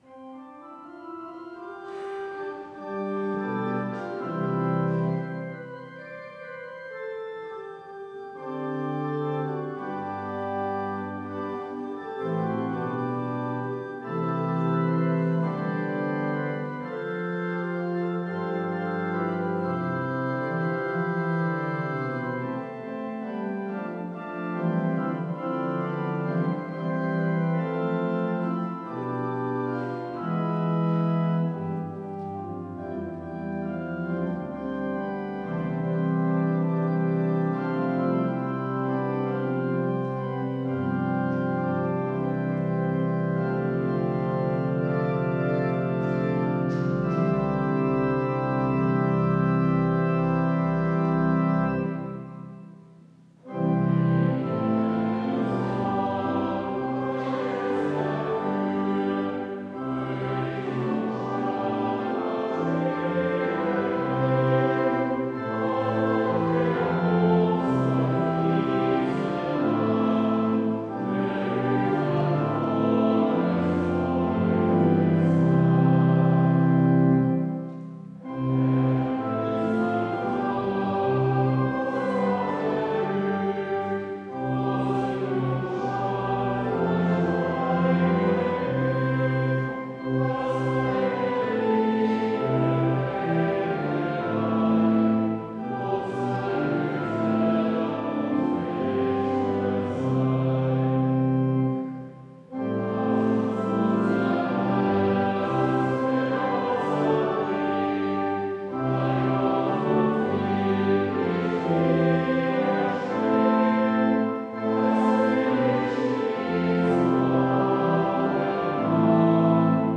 Audiomitschnitt unseres Gottesdienstes vom 3. Sonntag nach Trinitatis 2025.